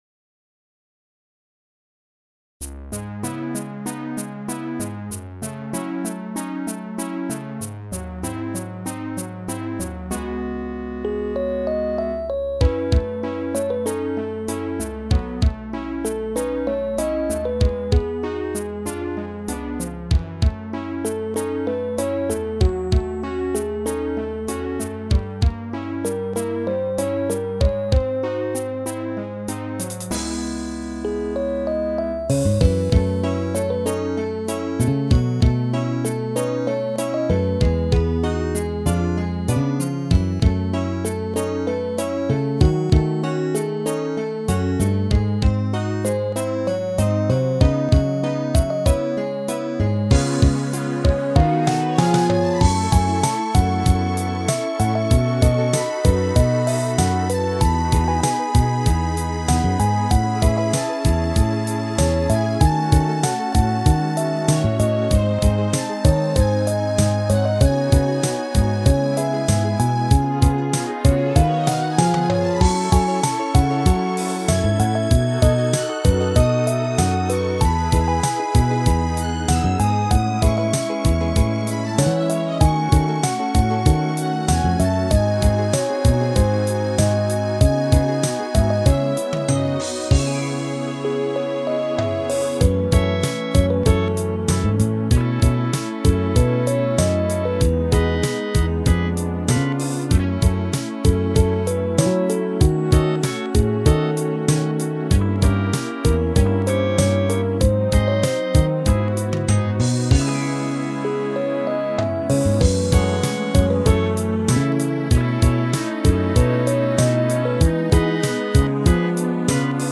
マイナー調のスローな8ビートはドラムがなくては間がもたない。
ギターはトップだけ拾ってあとはポジションなりに入力。
リフレインのラストにはエコー気味のボーカルを被せた。